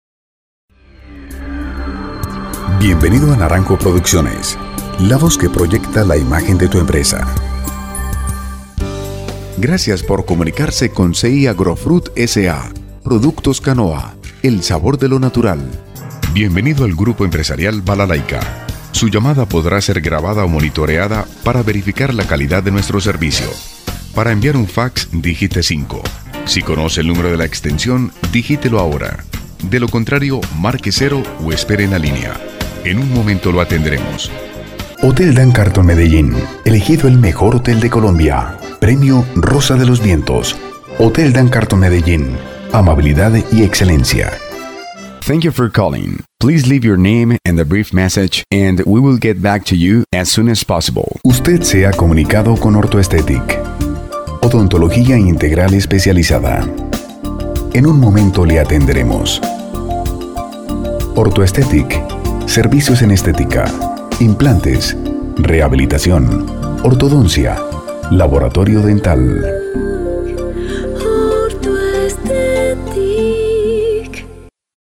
locutor comercial y corporativo, voz grave, voz cálida,
kolumbianisch
Sprechprobe: Sonstiges (Muttersprache):